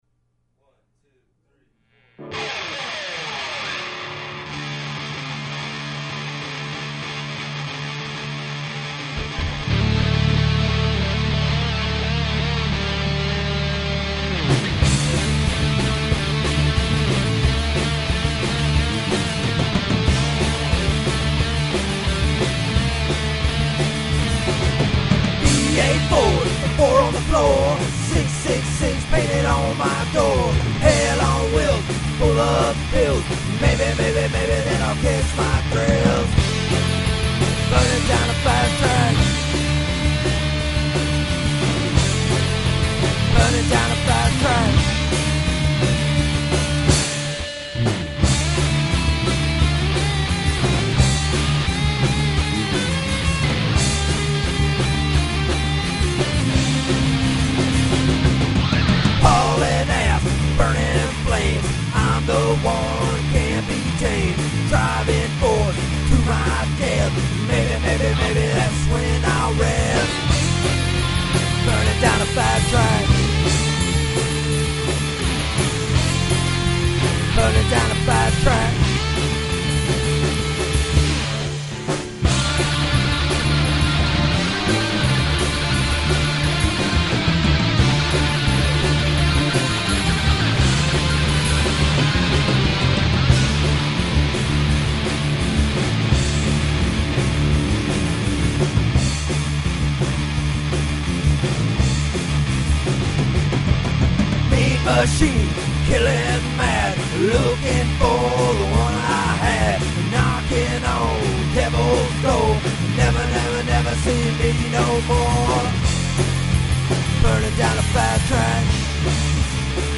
they came to my garage today to record a song